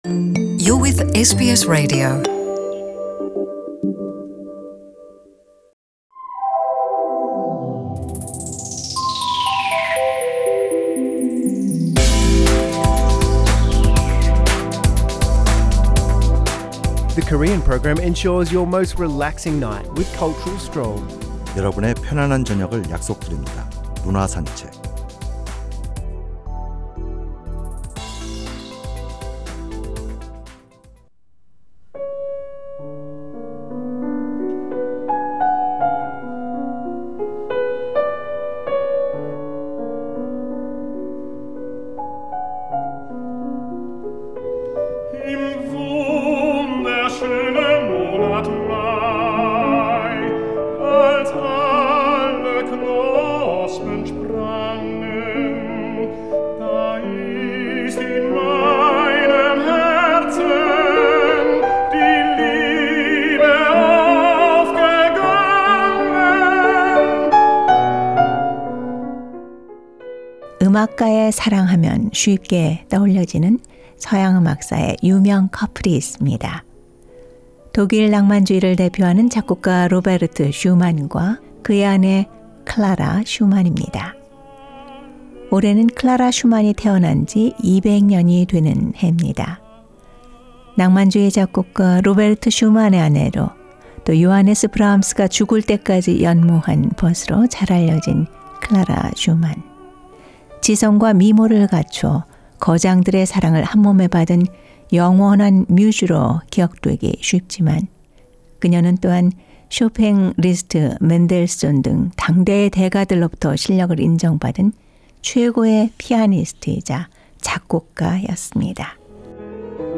The Weekly Culture Odyssey looks into arts and artists' life with background music, and presents a variety of information on culture, which will refresh and infuse with intellectual richness.